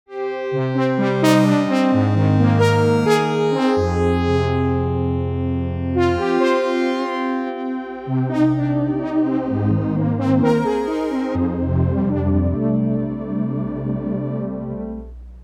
さりげないテープ・サチュレーション、霞んだリバーブ・テール、ビットクラッシュされたブレイクダウンまで、RC-20は常に“実験的な音作り”を誘います。
• Warm Low Focus 音の温かな抱擁。豊かな低音のうなりを加え、深みを増す